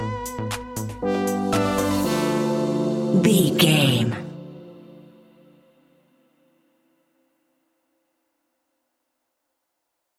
Aeolian/Minor
D
groovy
peaceful
smooth
drum machine
synthesiser
electro house
synth leads
synth bass